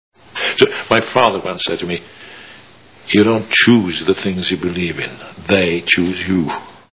Minority Report Movie Sound Bites